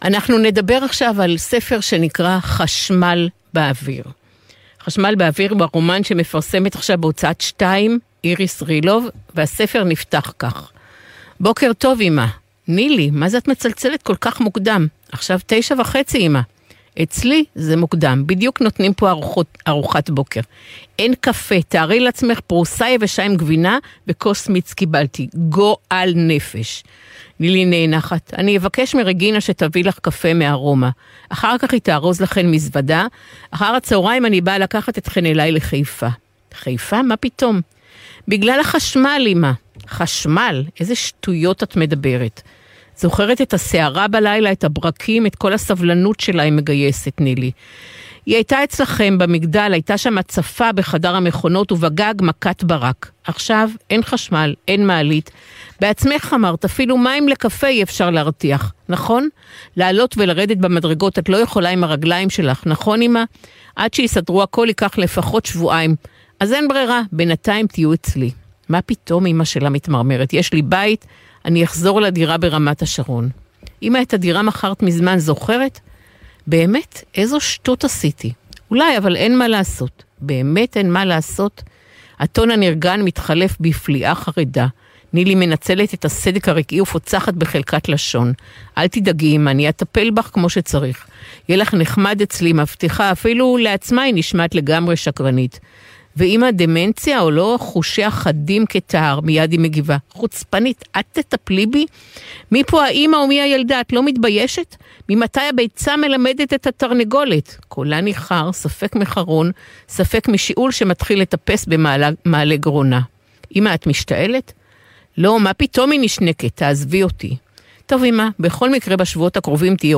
"ראיון בגל"צ בתכנית "ספרים רבותי ספרים